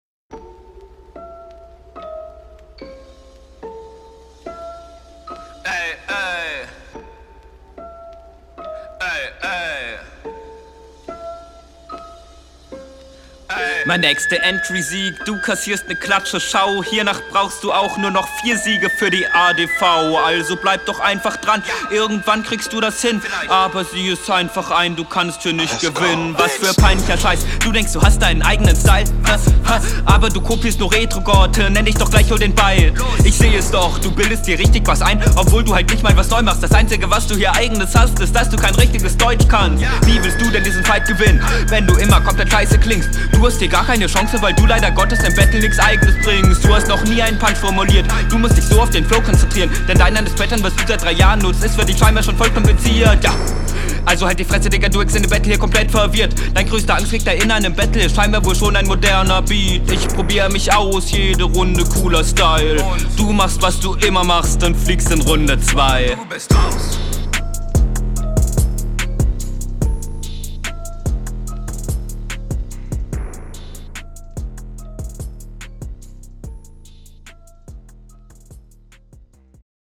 Du bist n bisschen laut, versuchst aber ganz gut, die Atmosphäre des Beat mit zu …